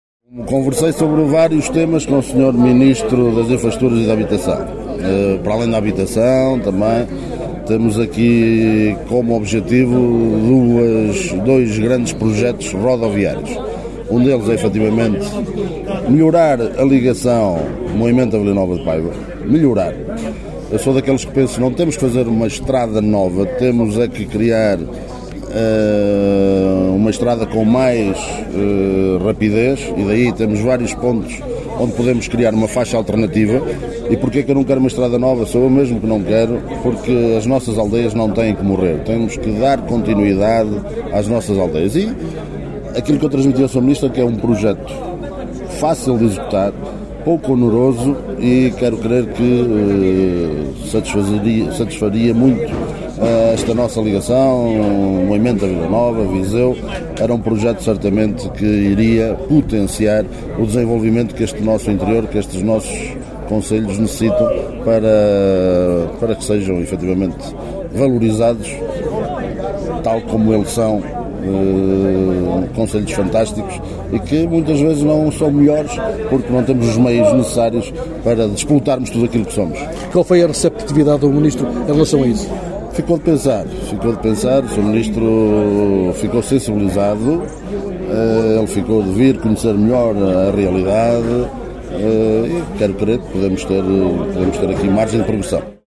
Depois de estar reunido recentemente, com Miguel Pinto Luz, Ministro das Infraestruturas e da Habitação, para além da variante a sul de Moimenta da Beira (projeto acordado com o anterior Governo e assinado com o IP – Infraestruras de Portugal), o IC 26 e a Estratégia Local de Habitação, Paulo Figueiredo, Presidente do Município de Moimenta da Beira, em declarações à Alive FM, disse que quer ver melhorada a ligação entre Moimenta da Beira e Vila Nova de Paiva/Viseu (estrada nacional 323), “era um projeto que iria potenciar o desenvolvimento…”, “quero querer que podemos ter margem de progressão…”.